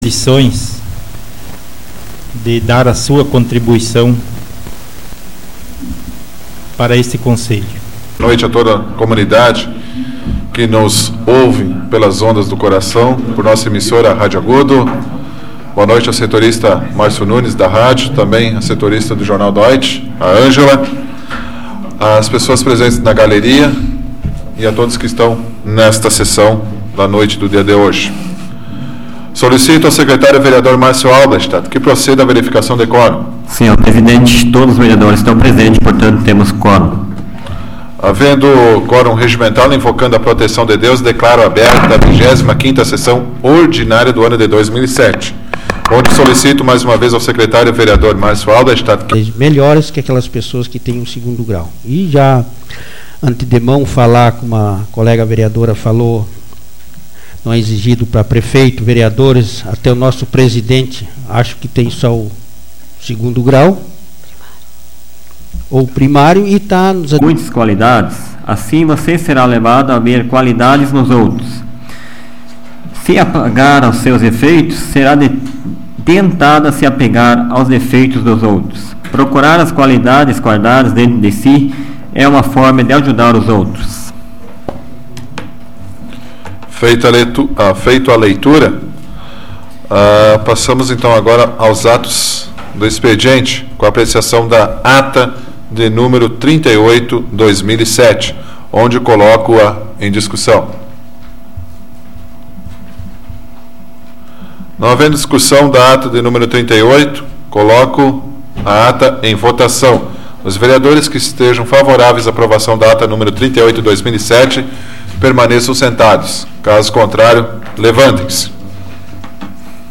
Áudio da 108ª Sessão Plenária Ordinária da 12ª Legislatura, 26 de novembro de 2007